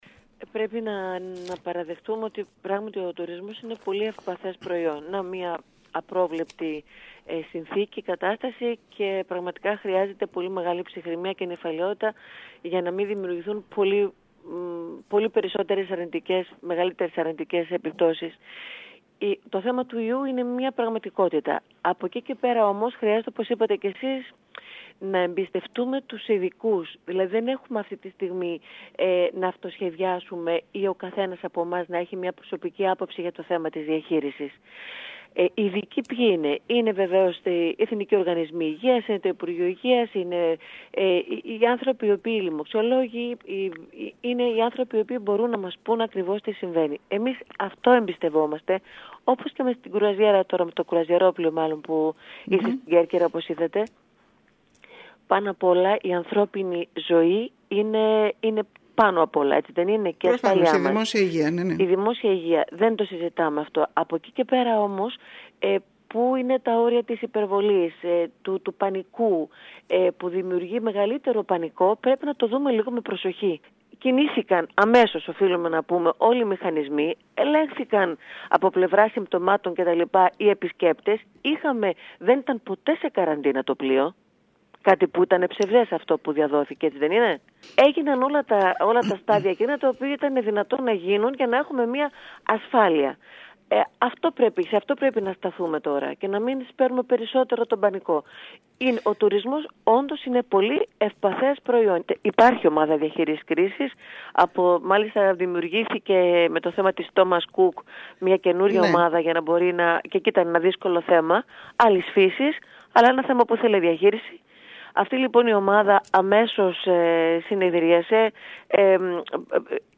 “Ο τουρισμός είναι ένα ευπαθές προϊόν”, δηλώνει μιλώντας στην ΕΡΤ Κέρκυρας για τις επιπτώσεις του κορωνοιού στον τουρισμό η πρόεδρος του ΕΟΤ Άντζελα Γκερέκου. Η κα Γκερέκου συνέστησε σε όλους να εμπιστεύονται τους ειδικούς και να επιδείξουν ψυχραιμία και νηφαλιότητα.